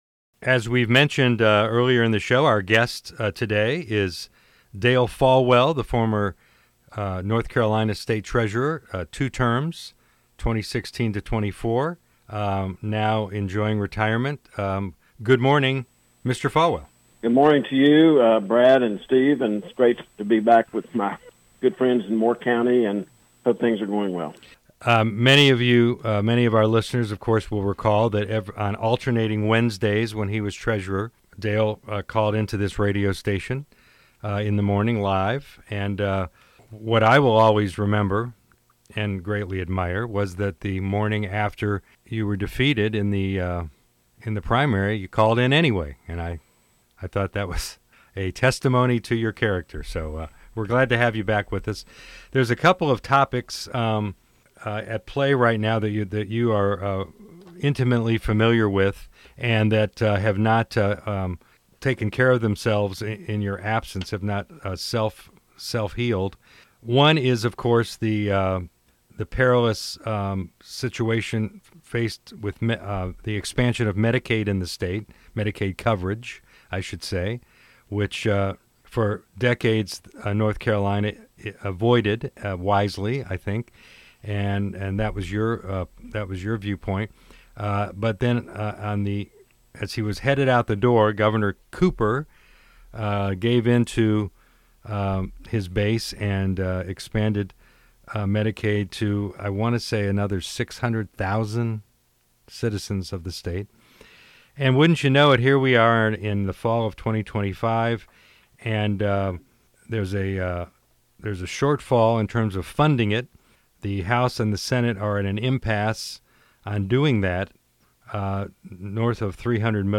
Taking Aim Dale Folwell Interview